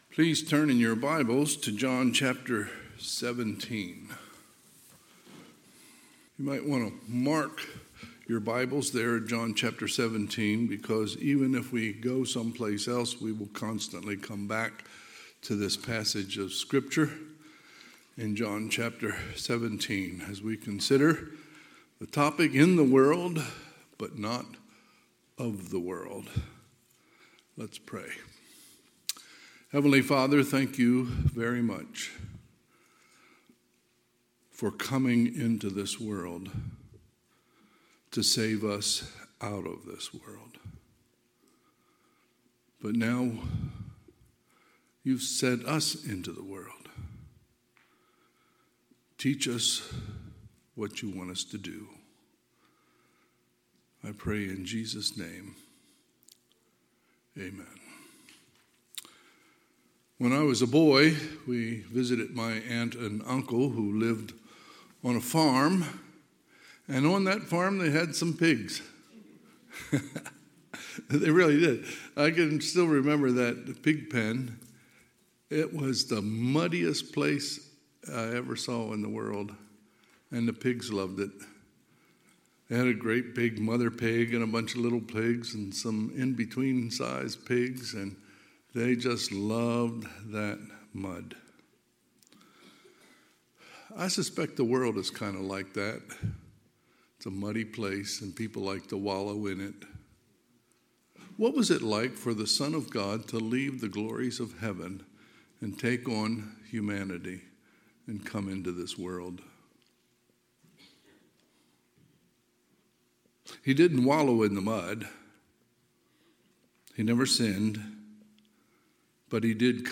Sunday, October 22, 2023 – Sunday AM
Sermons